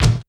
Kick_07.wav